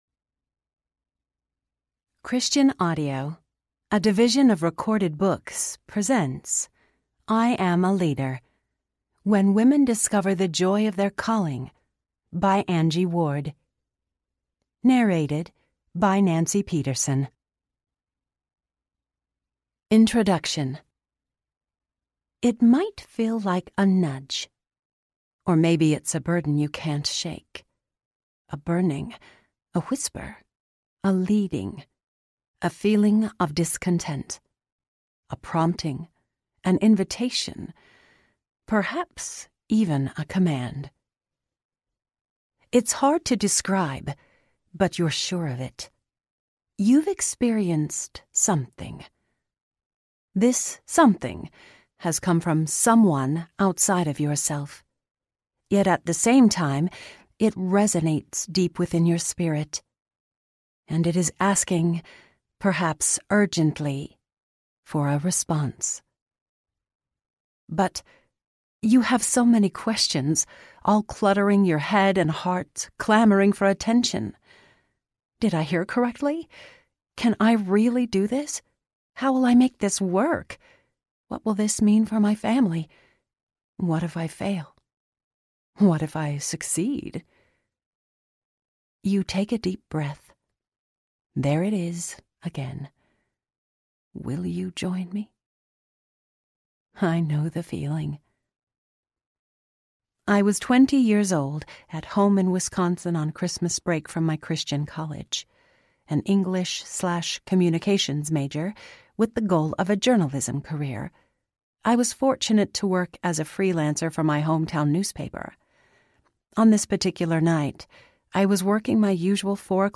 I Am a Leader Audiobook
Narrator
6.7 Hrs. – Unabridged